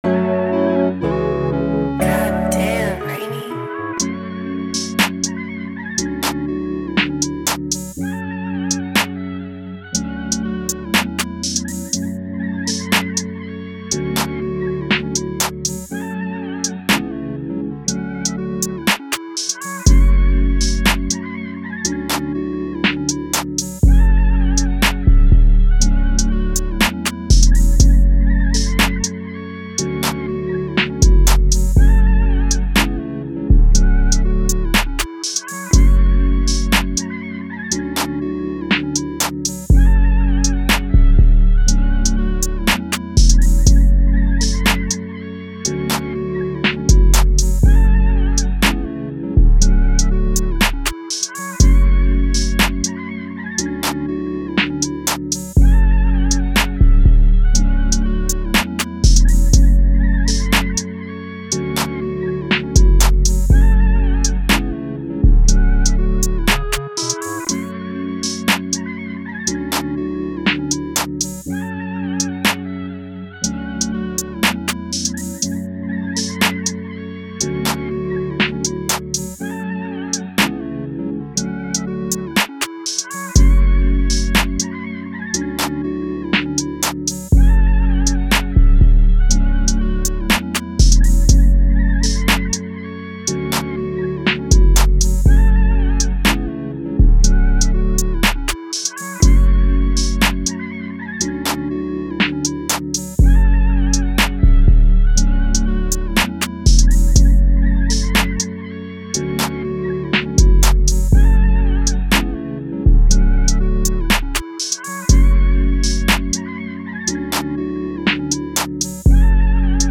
121BPM (RAP/ALTERNATIVE RAP)